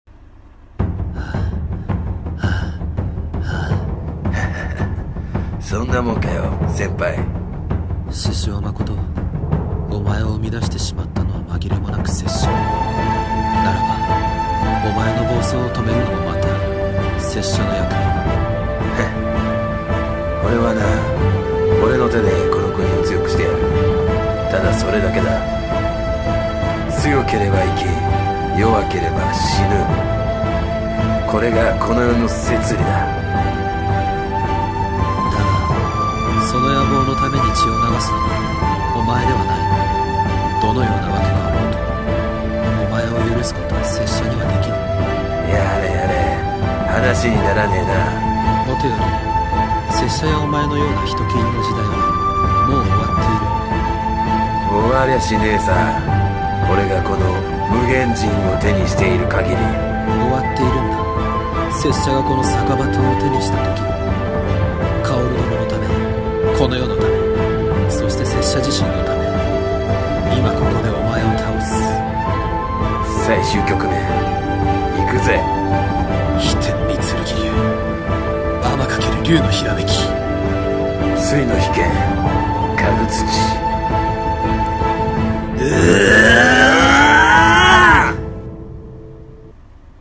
るろ剣声劇